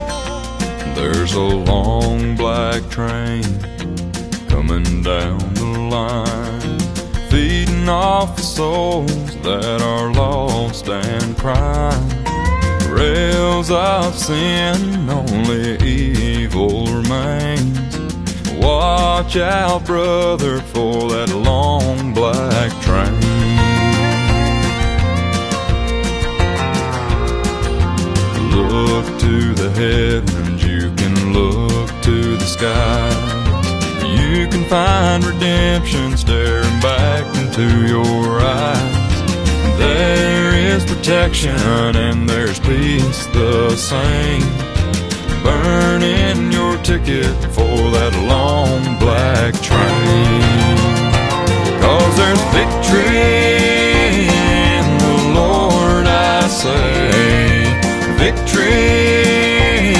My model of Southern 630 is back in action new decoder.